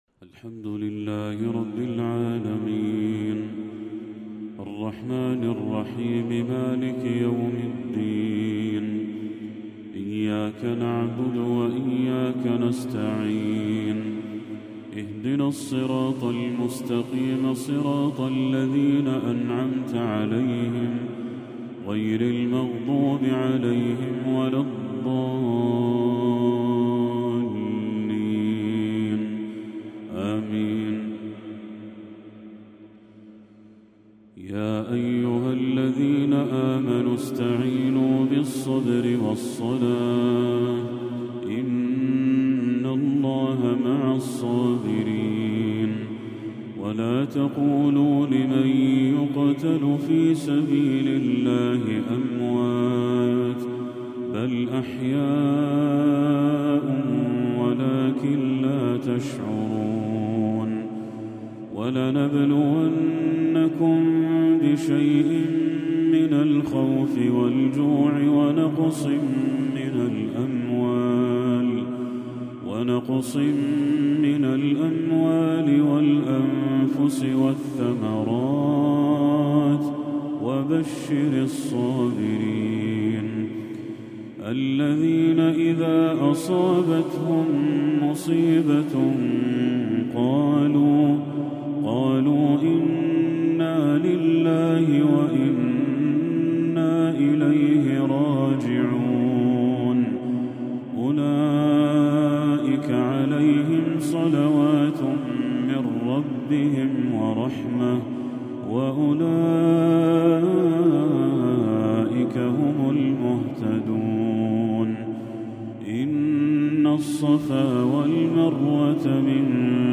تلاوة جميلة من سورة البقرة للشيخ بدر التركي | عشاء 29 ربيع الأول 1446هـ > 1446هـ > تلاوات الشيخ بدر التركي > المزيد - تلاوات الحرمين